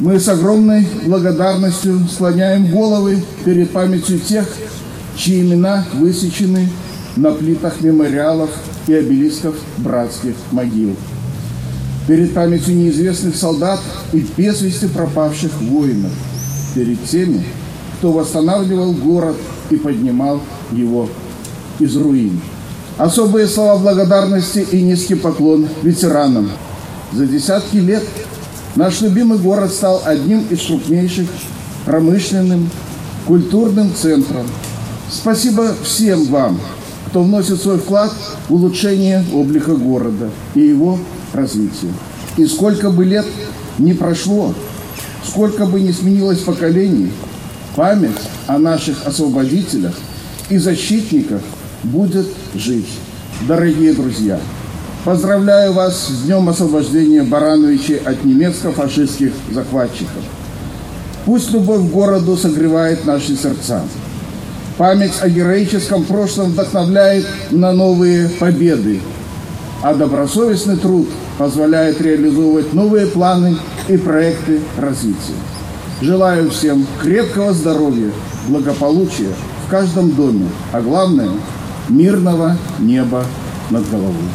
Главным событием торжественных мероприятий стал митинг у монумента воинам и партизанам «Вечный огонь».
К присутствующим обратился председатель горисполкома.  Юрий Громаковский поздравил всех с праздником и отметил, что война оставила тяжелый след в истории нашего города.